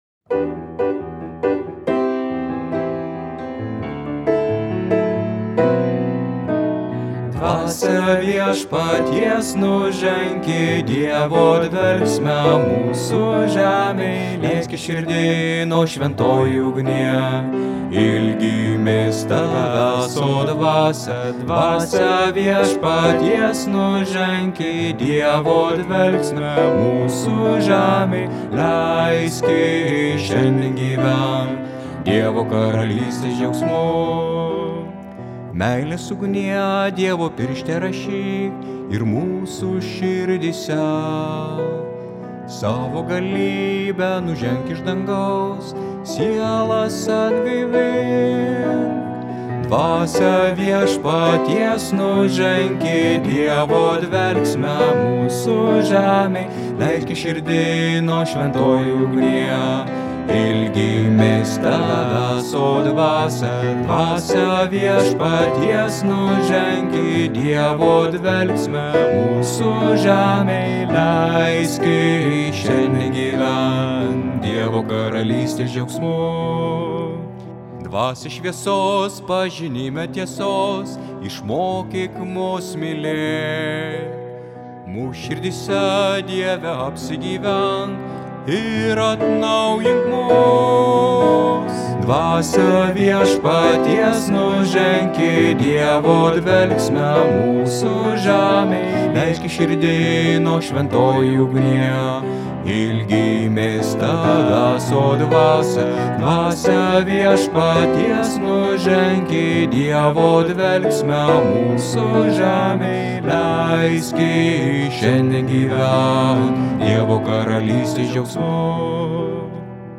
Bosas: